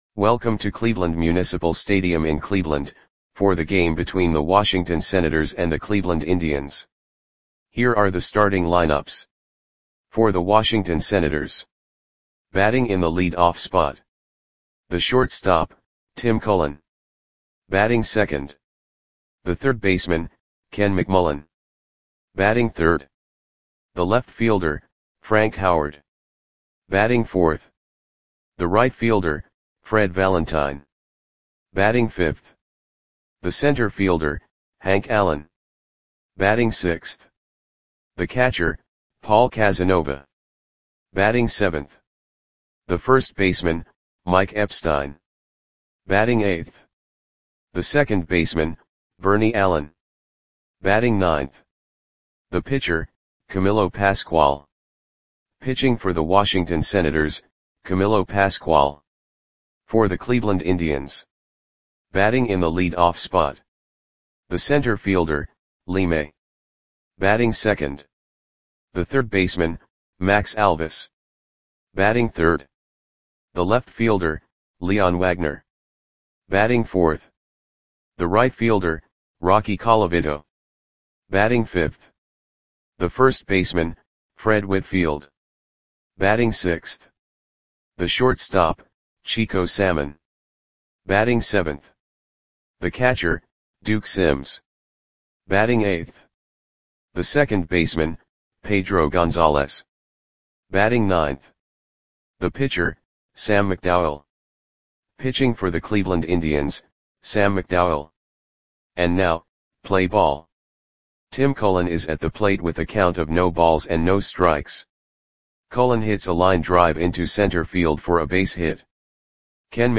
Audio Play-by-Play for Cleveland Indians on July 7, 1967
Click the button below to listen to the audio play-by-play.